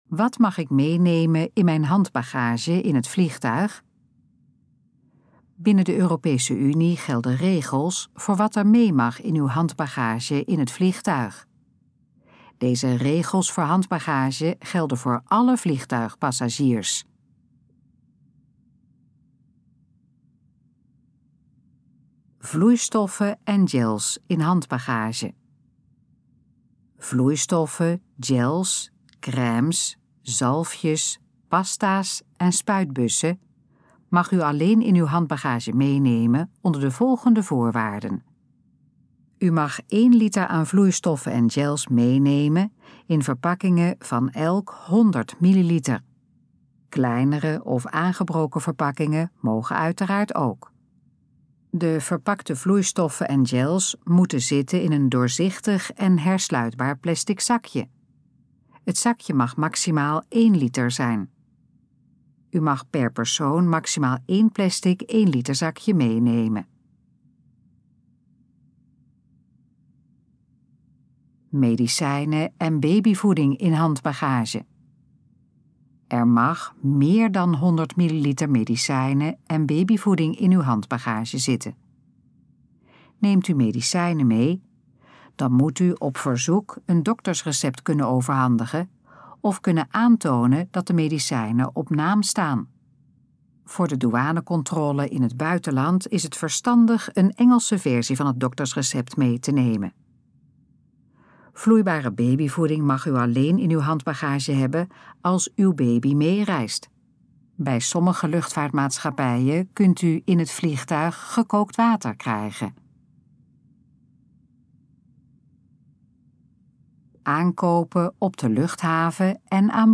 Dit geluidsfragment is de gesproken versie van de informatie op de pagina Wat mag ik meenemen in mijn handbagage in het vliegtuig?